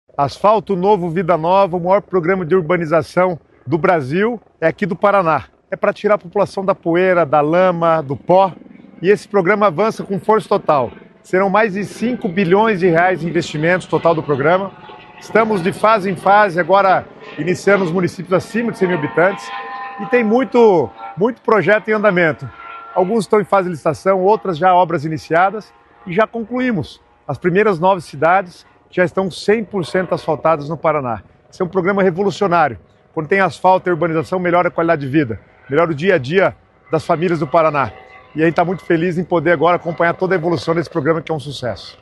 Sonora do secretário das Cidades, Guto Silva, sobre o avanço do programa Asfalto Novo, Vida Nova